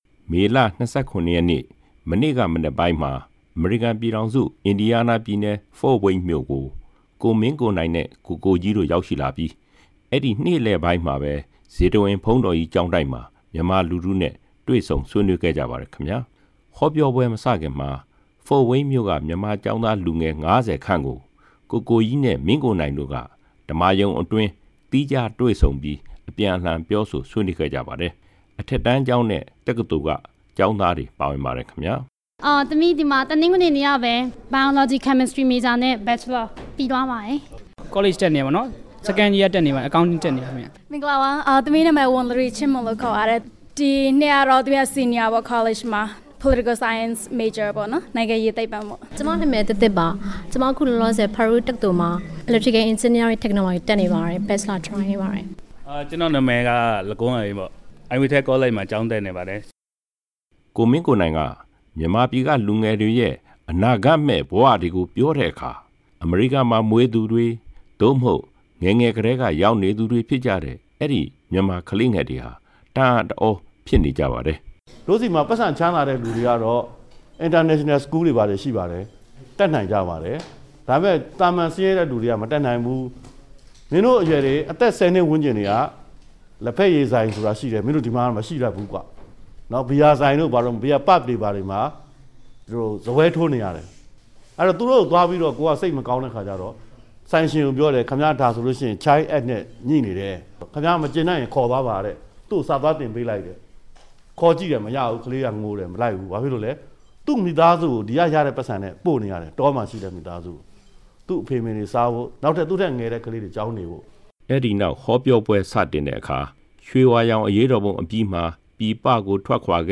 မေလ ၂၇ ရက်နေ့က အင်ဒီယားနားပြည်နယ် ဖို့ဝိန်းမြို့ကို ကိုမင်းကိုနိုင်နဲ့ ကိုကိုကြီးတို့ ရောက်ရှိသွားပြီး ဇေတဝန် ဘုန်းတော်ကြီးကျောင်းတိုက်မှာ ဟောပြောပွဲ မစခင် ဒေသခံမြန်မာကျောင်းသားလူငယ် ၅ဝ ခန့်ကို ဓမ္မာရုံအတွင်း သီးခြားတွေ့ဆုံပြီး အပြန်အလှန်ပြောဆို ဆွေးနွေးခဲ့ကြရာ မှာ ကိုမင်းကိုနိုင်က အဲဒီလိုပြောဆိုခဲ့တာဖြစ်ပါတယ်။